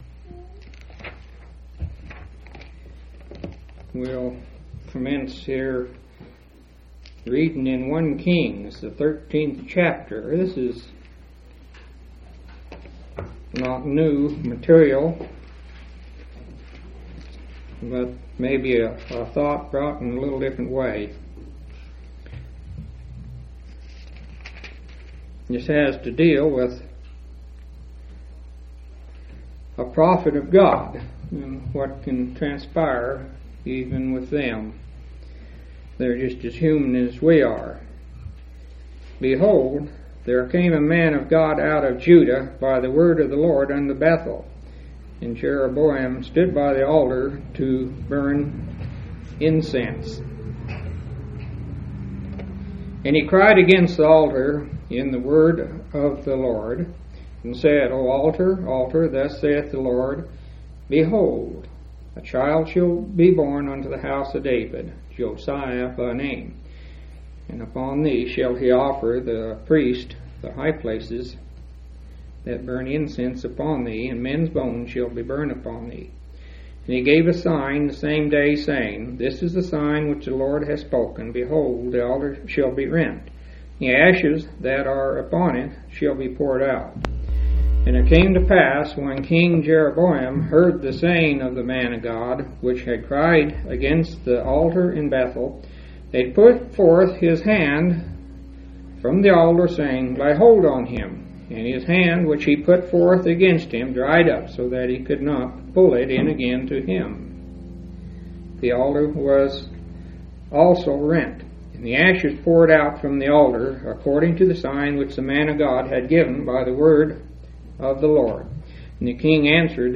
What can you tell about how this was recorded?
9/20/1987 Location: Grand Junction Local Event